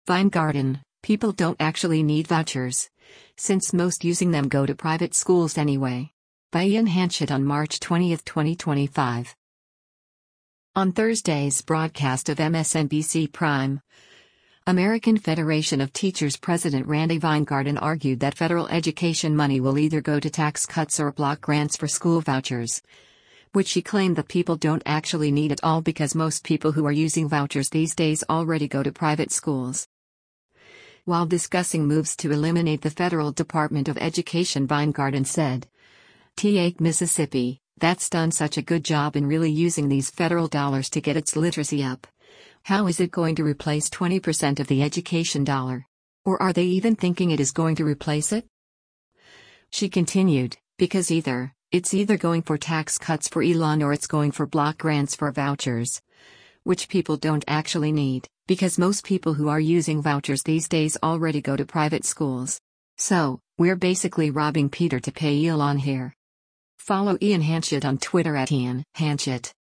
On Thursday’s broadcast of “MSNBC Prime,” American Federation of Teachers President Randi Weingarten argued that federal education money will either go to tax cuts or block grants for school vouchers, which she claimed that “people don’t actually need” at all “because most people who are using vouchers these days already go to private schools.”